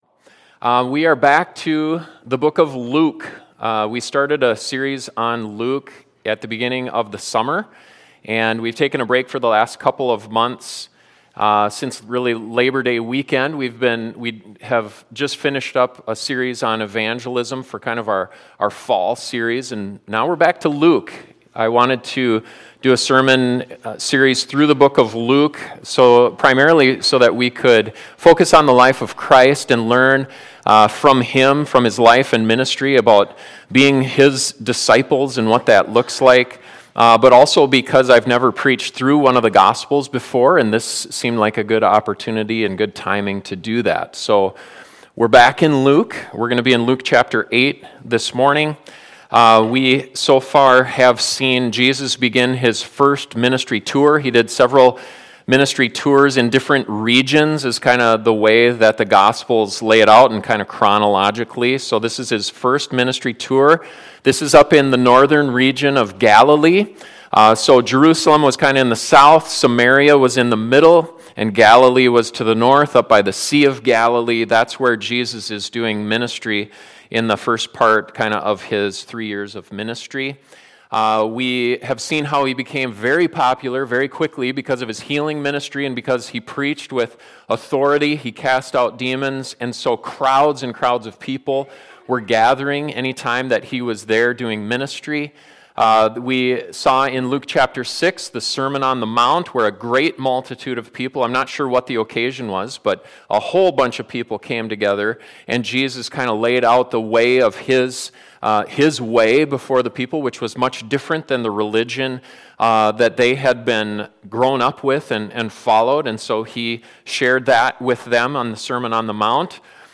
2022 The Soil of Our Hearts Pastor